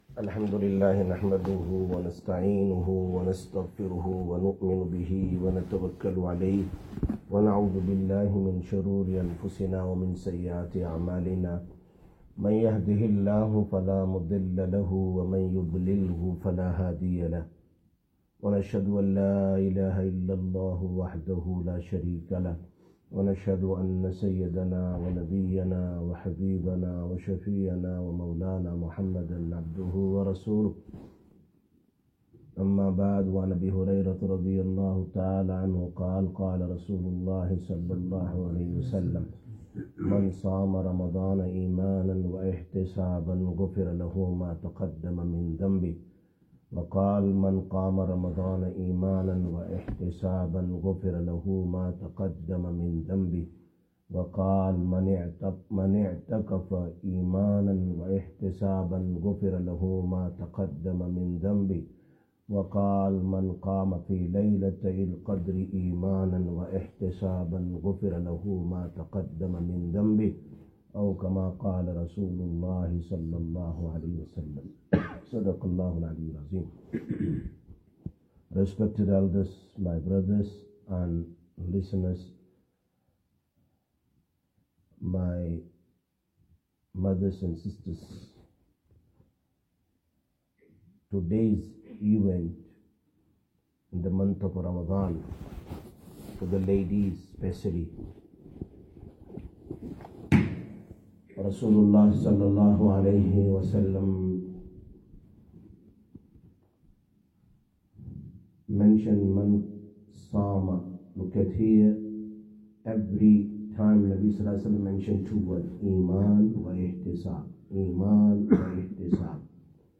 09/03/2025 Masjid Ur Rashideen Bayaan